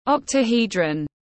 Hình bát diện tiếng anh gọi là octahedron, phiên âm tiếng anh đọc là /ˌɒk.təˈhiː.drən/.
Octahedron /ˌɒk.təˈhiː.drən/